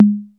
808 CONGA.wav